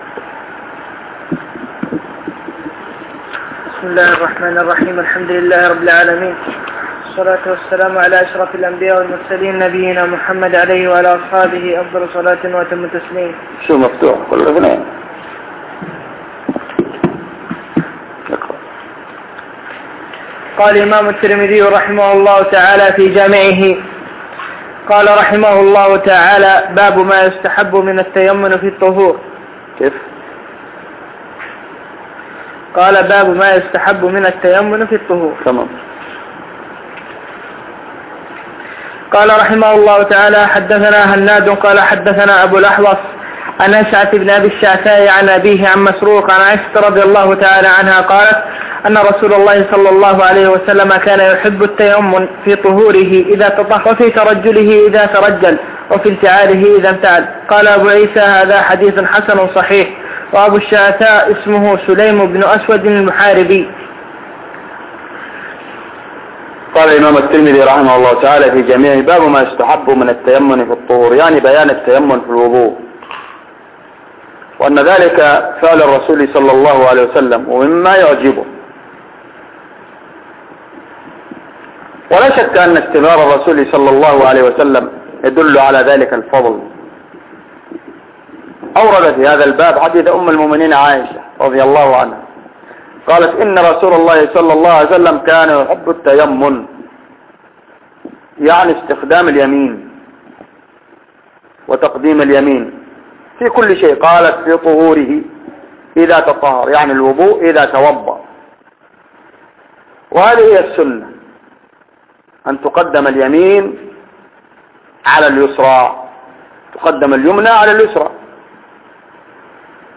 سلسلة دروس شرح جامع الترمذي